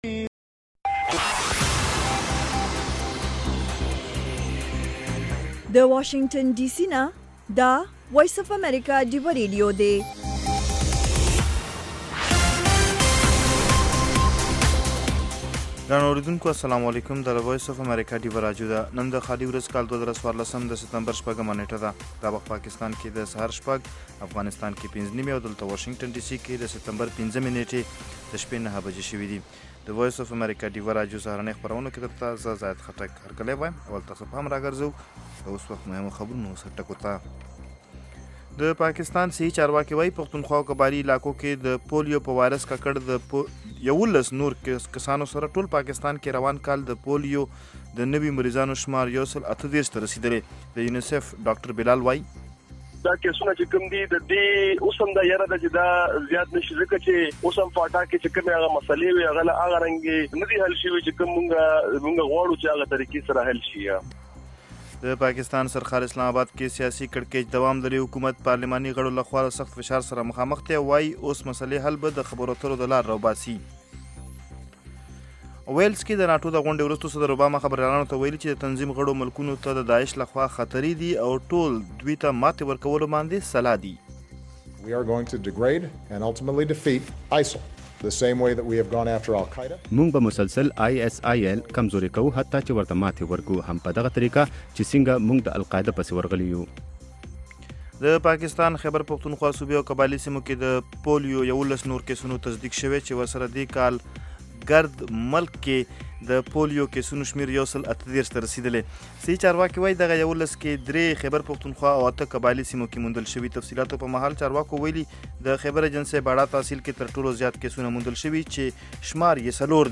خبرونه - 0100